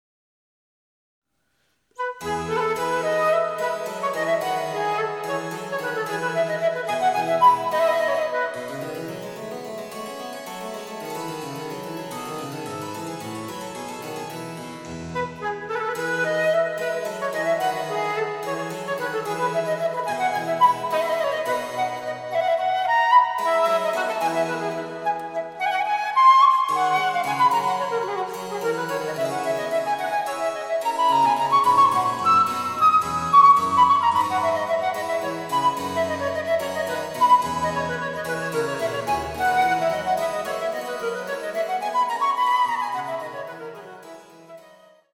第3楽章はメランコリックなシチリアーナ（シチリアふう舞曲）で、８分の６拍子。
■フルートによる演奏